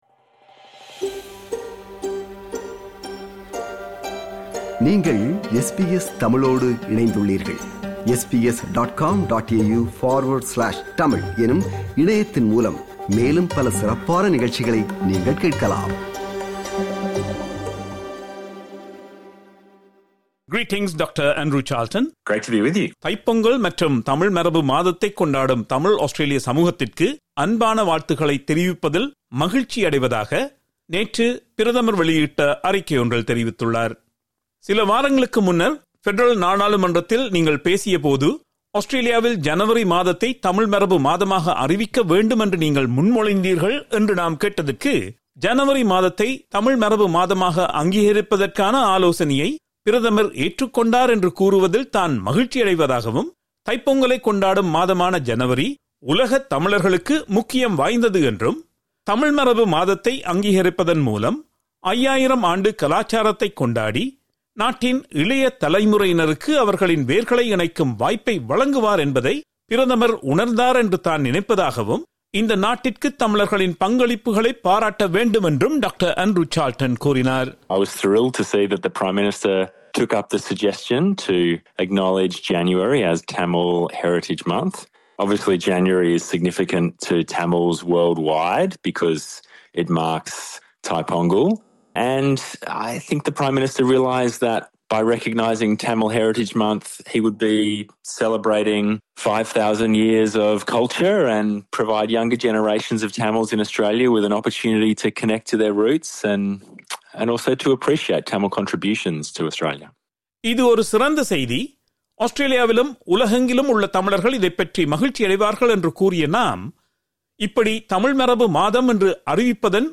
தமிழர்களுக்கு ஜனவரி மாதம் ஏன் முக்கியத்துவம் பெறுகிறது என்பது குறித்தும் தமிழ் மரபைக் கொண்டாடுவதன் முக்கியத்துவம் என்ன என்பது குறித்தும் பரமற்றா நாடாளுமன்ற உறுப்பினர் டாக்டர் ஆண்ட்ரூ சார்ல்டன் அவர்களிடம் உரையாடுகிறார்